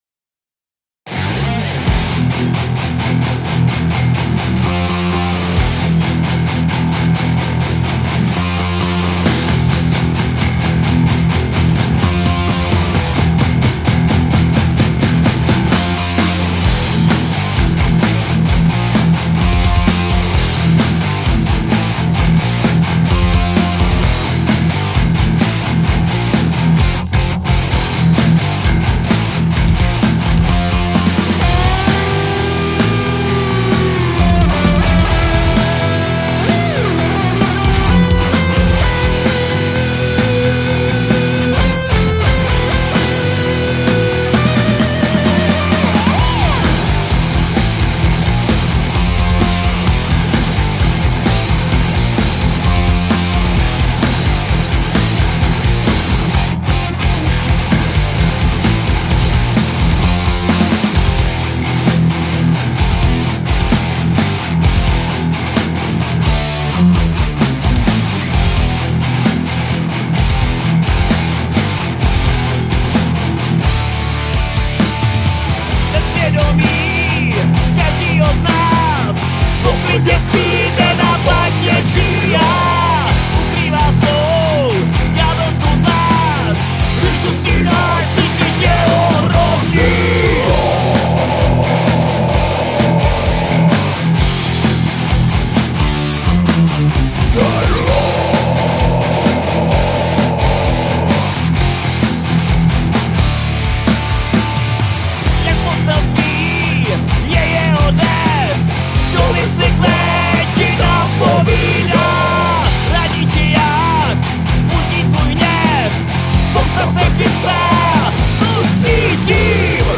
Žánr: Metal/HC